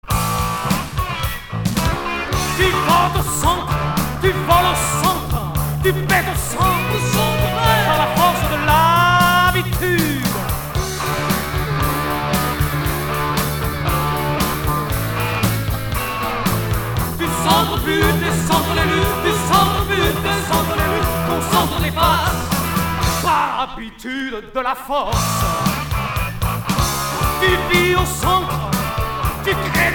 Rock hard